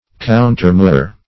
Countermure \Coun`ter*mure"\ (koun`t?r-m?r"), v. t. [imp. & p.